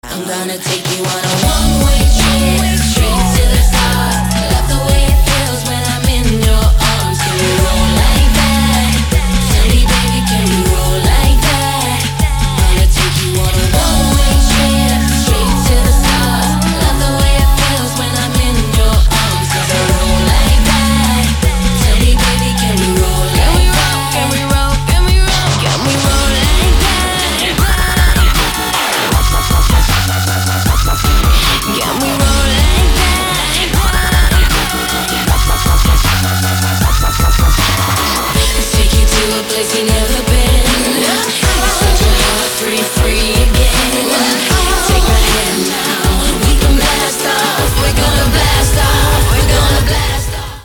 • Качество: 256, Stereo
женский вокал
dance
спокойные
клавишные
пианино
vocal